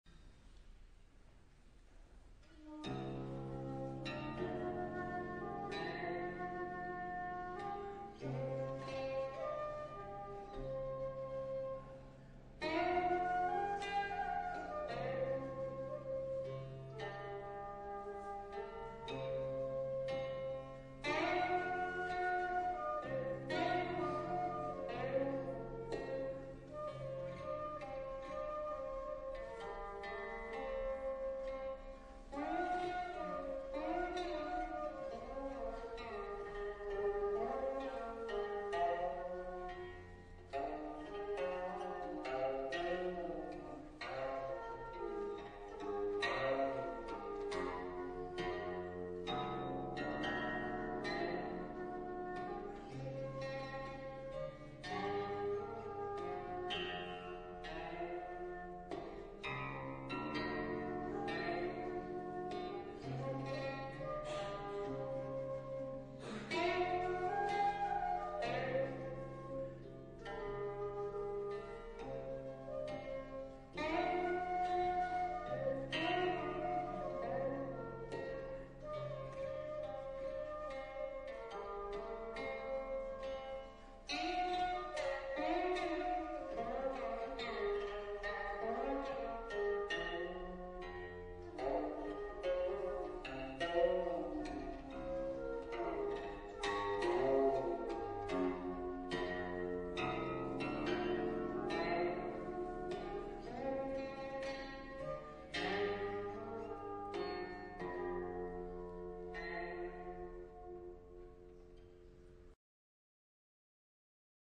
цитра цинь и флейта сяо F